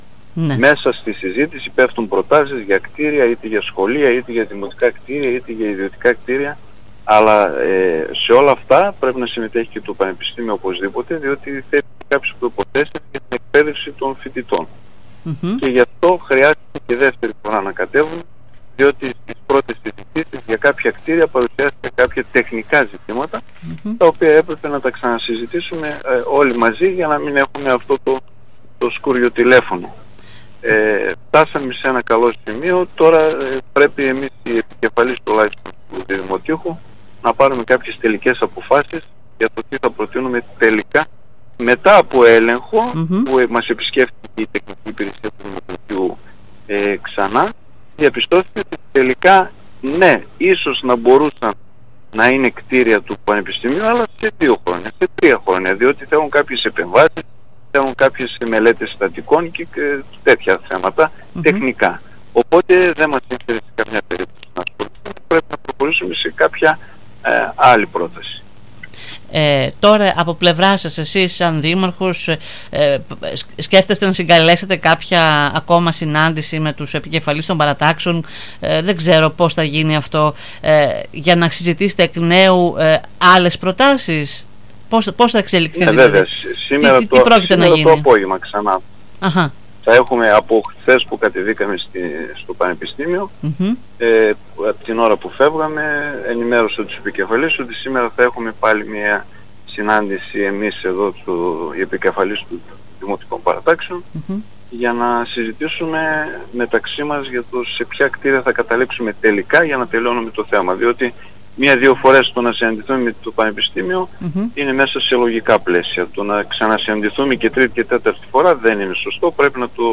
Ιδιαίτερα προβληματισμένος για το γεγονός εναλλαγής προτάσεων κάθε φορά, φάνηκε ο δήμαρχος Διδυμοτείχου μιλώντας σήμερα στην ΕΡΤ Ορεστιάδας